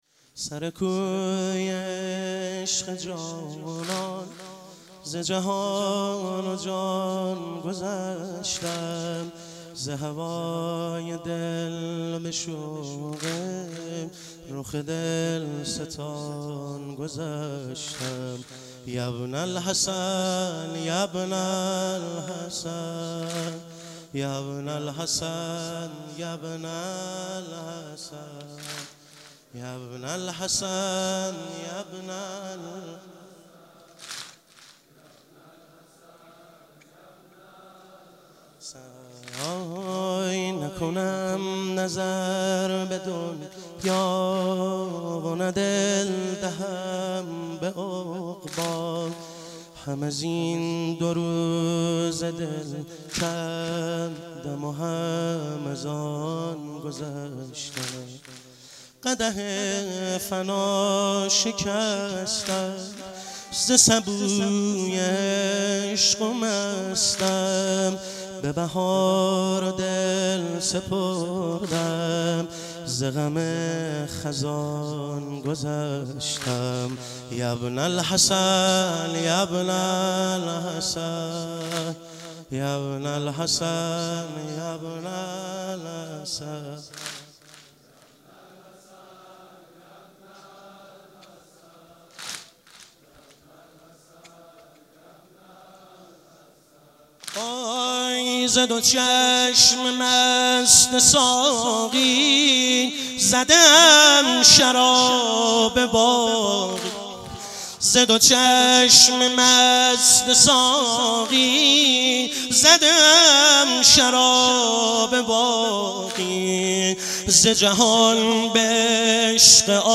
مشهد الرضا - واحد - 6 - 1395